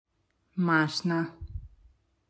6. Mašna (click to hear the pronunciation)